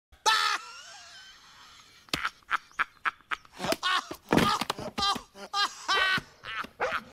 woody-laugh-toy-story.mp3